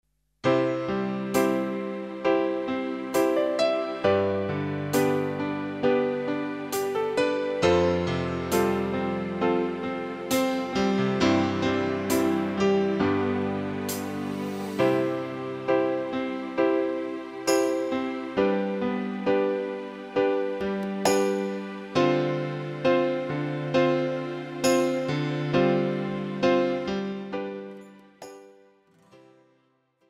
Backing track Karaoke
Pop, 2010s